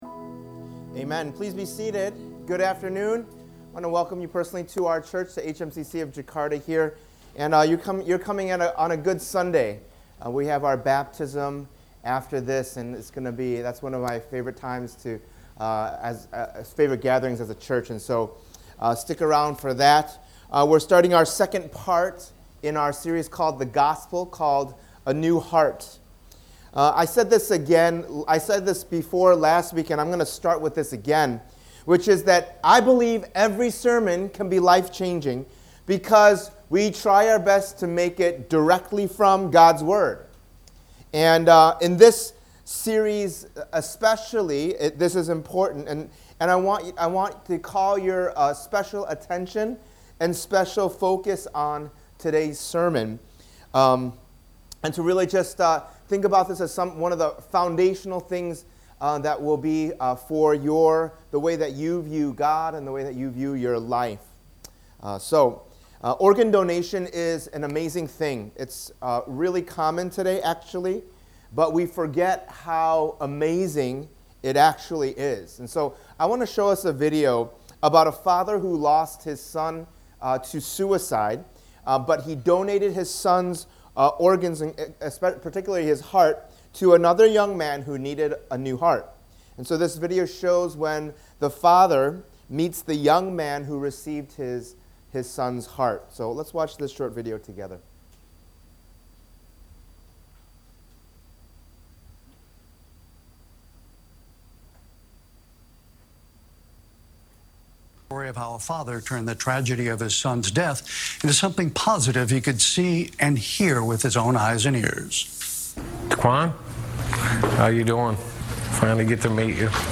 In this three-part sermon series, we’ll see how the Gospel fits into God’s redemptive history in Part 1: The Full Picture, and then what the Gospel does in us in Part 2: A New Heart, and finally how the Gospel works through us in Part 3: Good Works.